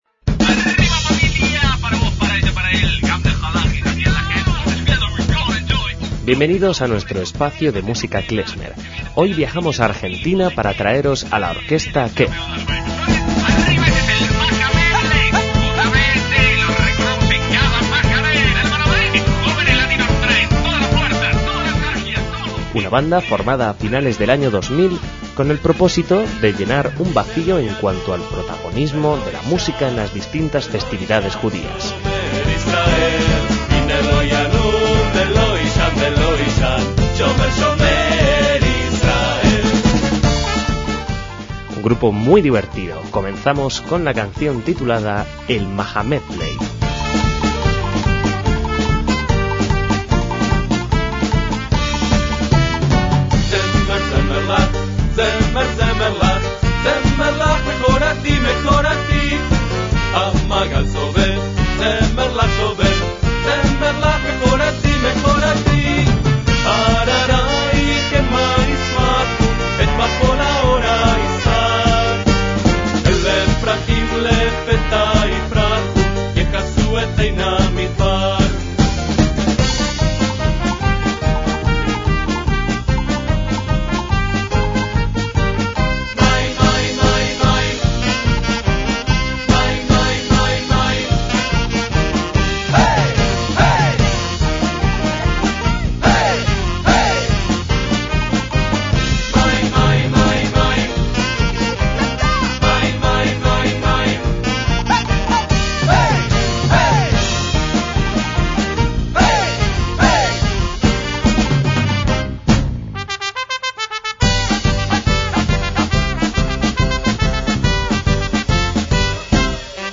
MÚSICA KLEZMER - La Orquesta Kef se especializa en música judía.